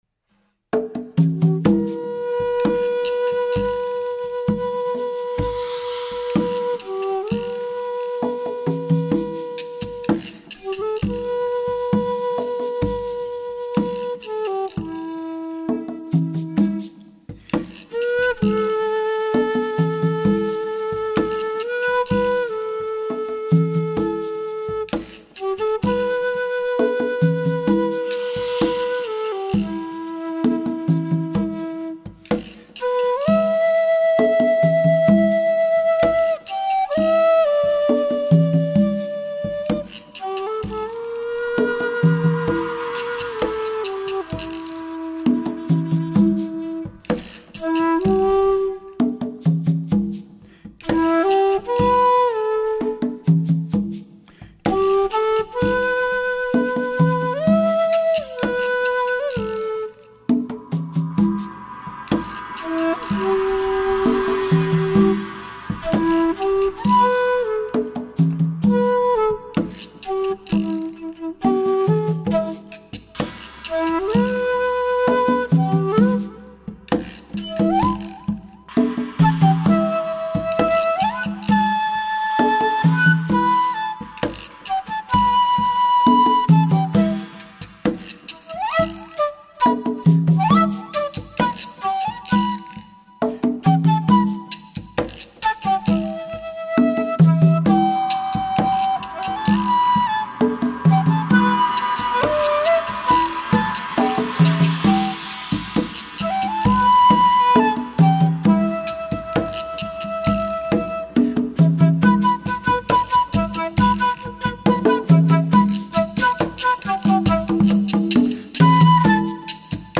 A traditional melody, usually "motherless", yet I'm personally only "fatherless"... The traditional melody "Wayfaring Stranger" blends in midway.
I'm playing an E-Shakuhachi
congas, Guiro, Afuché (beaded shaker/rasp), and a Chinese cymbal